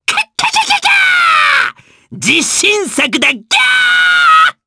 Lakrak-Vox_Skill5_jp_b.wav